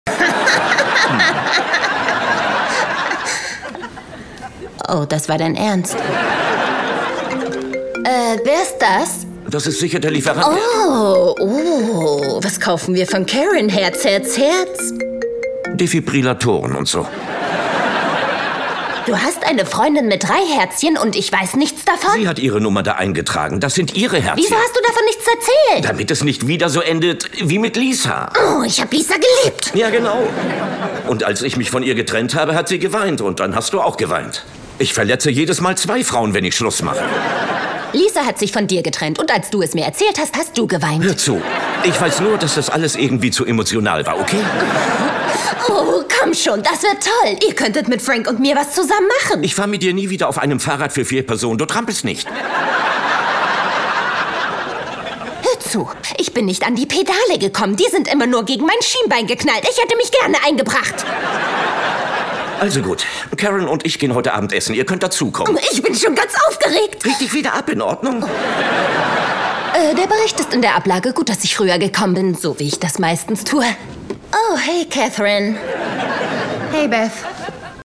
Voice Over Dokumentation